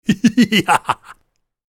Short Human Laugh Sound Effect
Sinister-comical-laugh-sound-effect.mp3